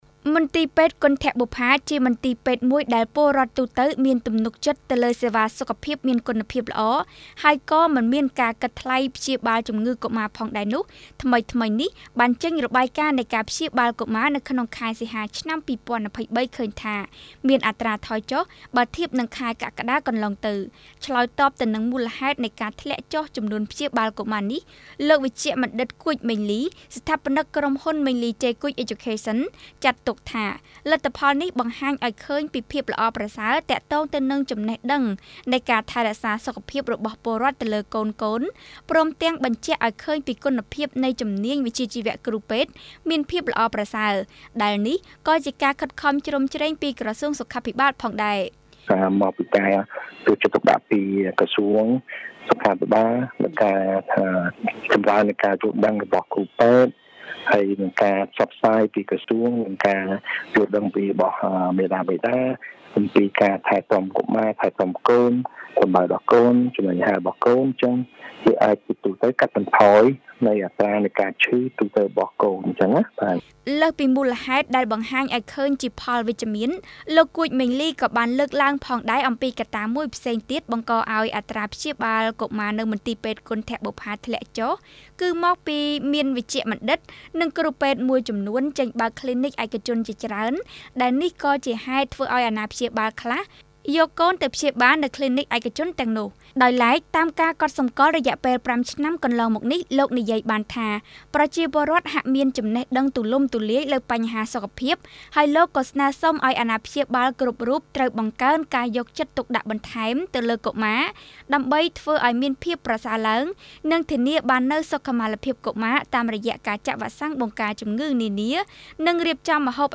ជូនសេចក្តីរាយការណ៍